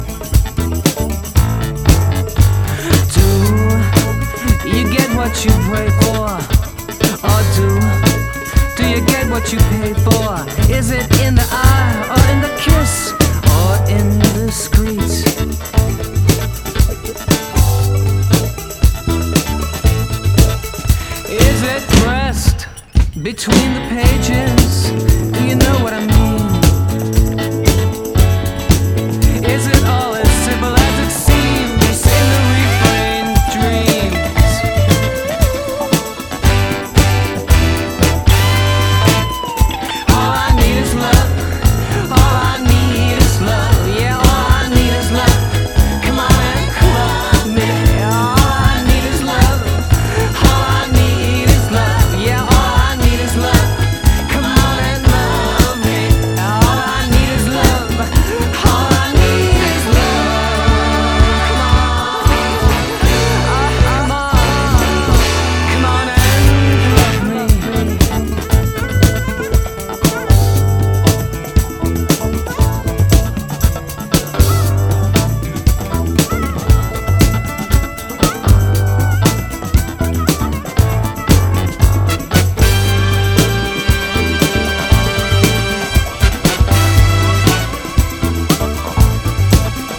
ジャンル(スタイル) DEEP HOUSE / DISCO / SOUL